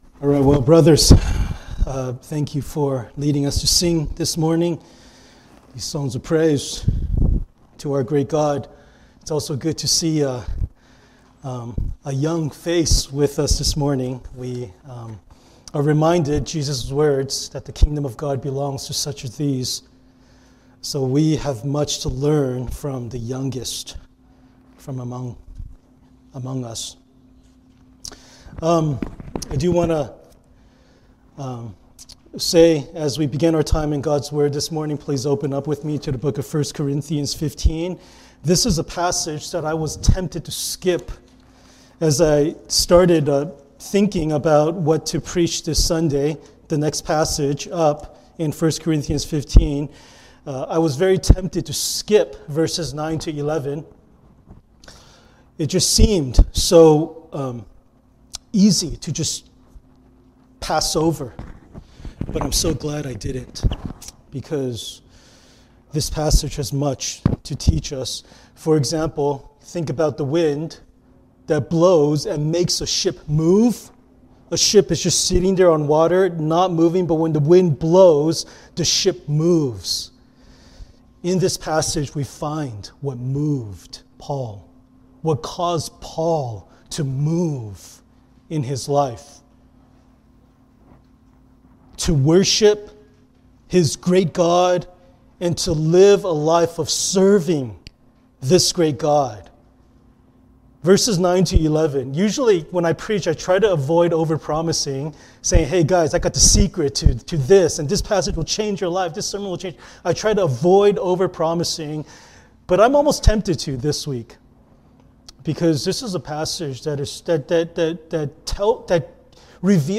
Passage: 1 Corinthians 15:9-11 Service Type: Sunday Worship